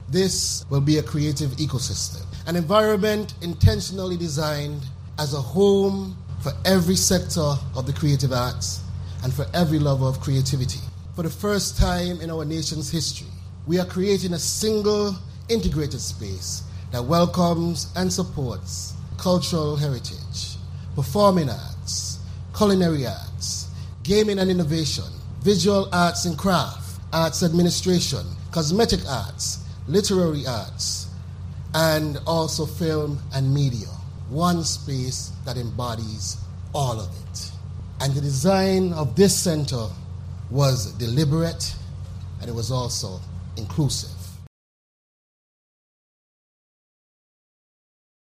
Addressing stakeholders at the ceremony, Minister Duggins said: